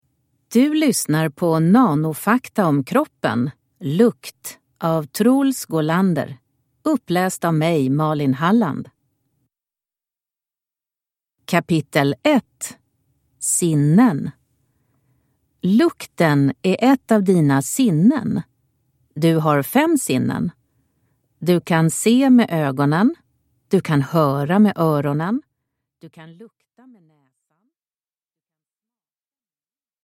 Nanofakta om kroppen. Lukt – Ljudbok